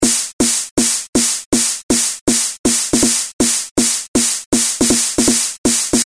标签： 160 bpm Hardcore Loops Synth Loops 1.01 MB wav Key : Unknown
声道立体声